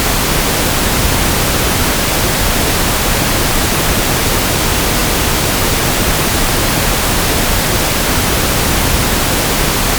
This can be used to generate coloured noise in human-audible range:
10 seconds of pink noise and save it to a file as follows: